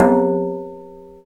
Index of /90_sSampleCDs/Roland L-CD701/PRC_FX Perc 1/PRC_Long Perc
PRC TUBULA09.wav